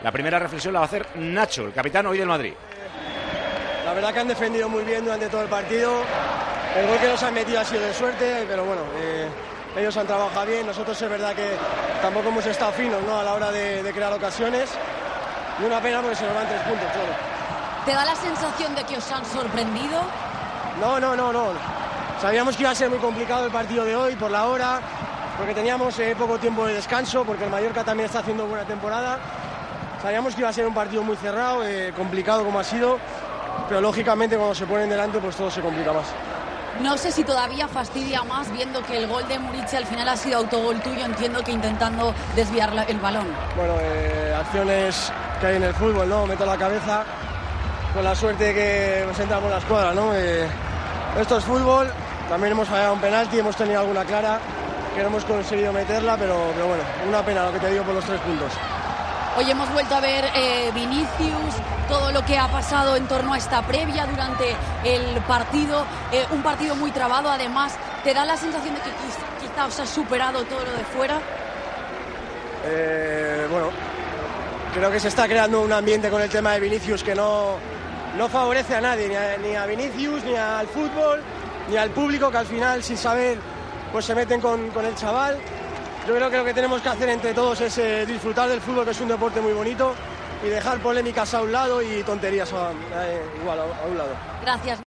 Nacho Fernández, capitán este domingo en Mallorca del Real Madrid, analizó la derrota de su equipo en Son Moix (1-0) en declaraciones a Movistar + y habló sobre todo lo que rodea a Vinicius después de otro partido tenso con el jugador brasileño.